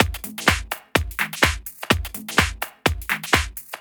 • washed mid techno-house fill.wav
washed_mid_techno-house_fill_YIS.wav